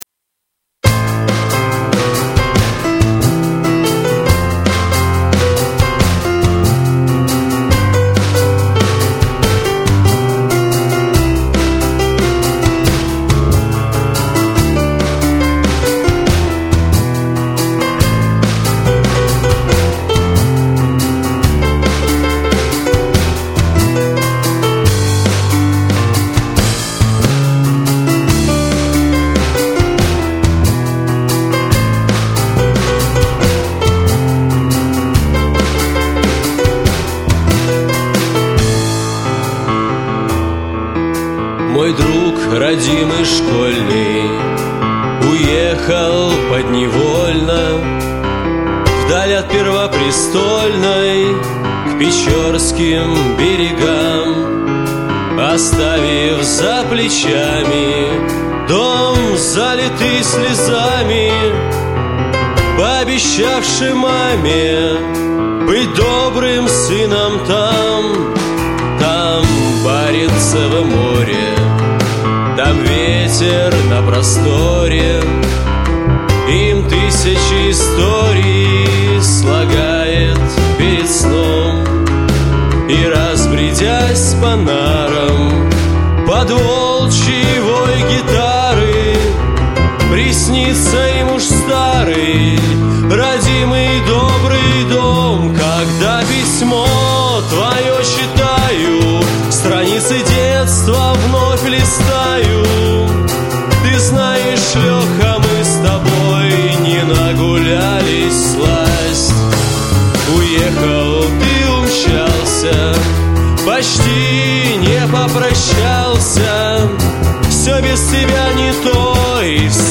• Жанр: Шансон